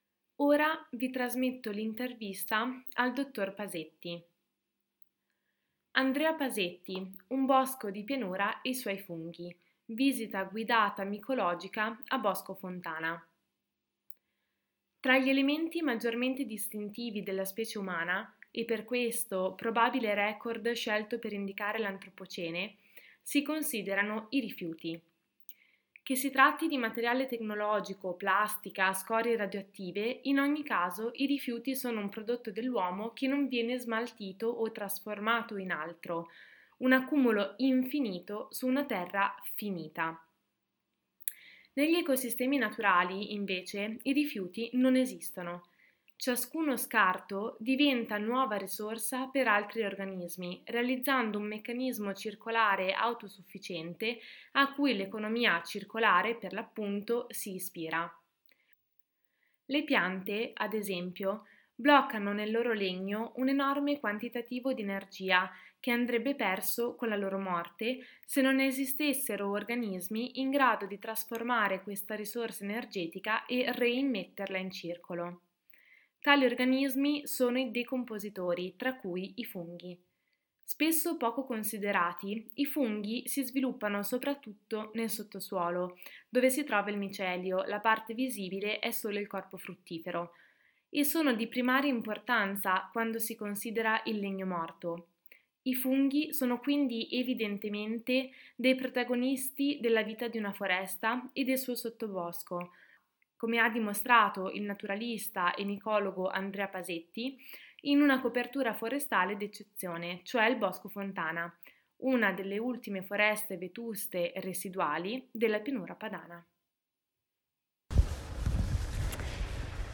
Visita guidata micologica a Bosco Fontana